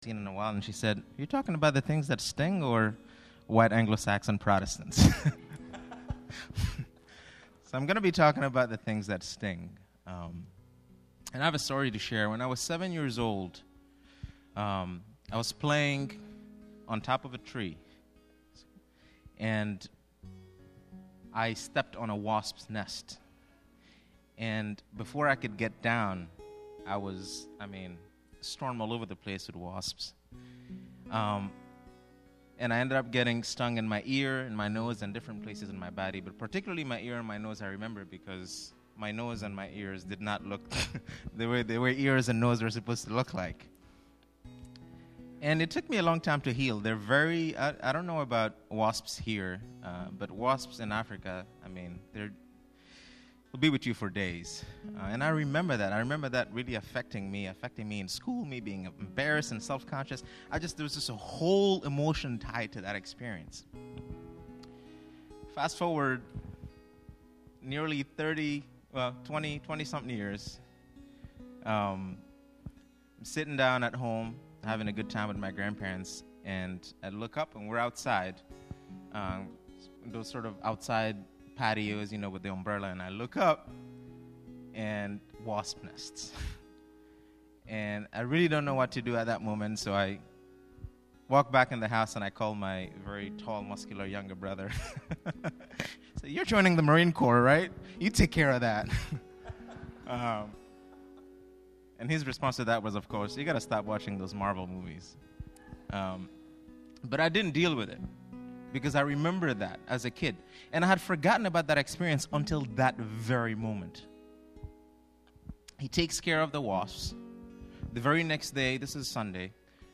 On this Pentacost Sunday